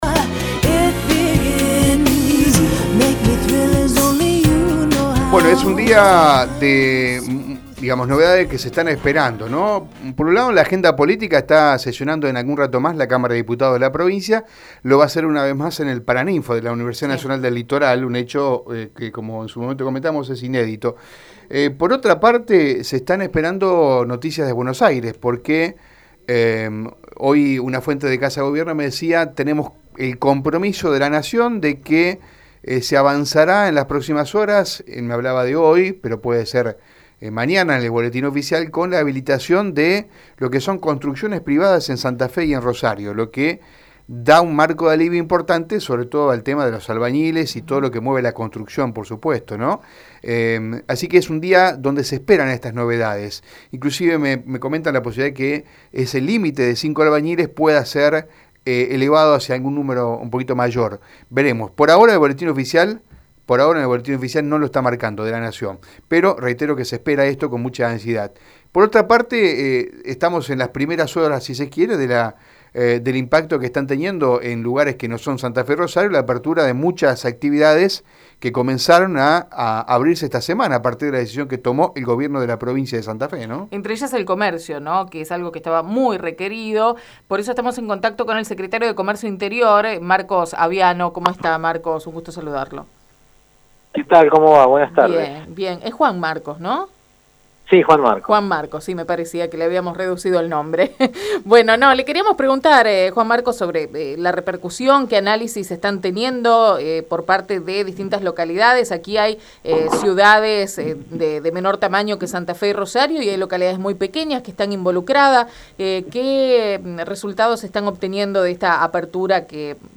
En dialogo con Radio EME, el secretario de Comercio Interior de la provincia de Santa Fe habló sobre como preparan la flexibilización de la actividad comercial en algunas regiones del territorio provincial.